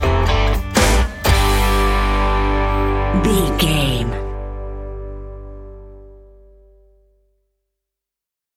Ionian/Major
electric guitar
drums
bass guitar